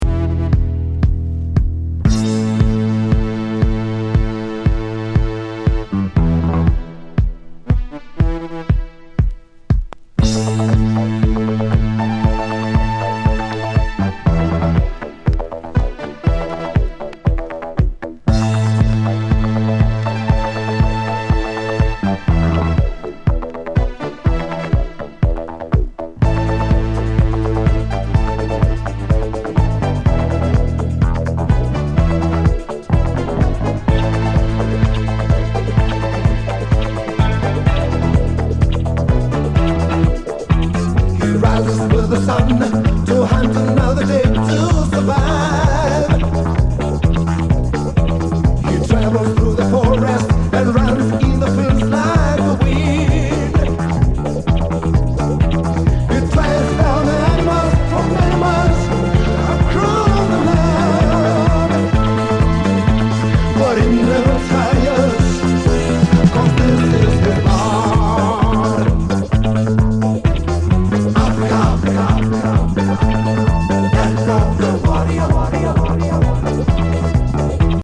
スペーシー・ロッキン・アフロ・ディスコ